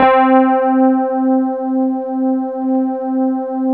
RHODES C3.wav